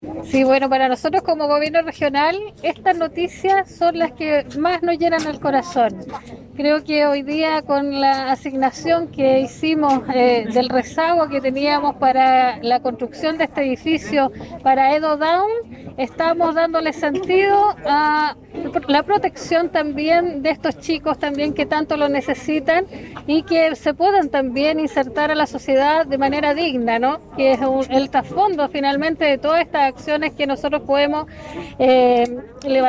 GOBERNADORA-K.NARANJO.PENALOZA.mp3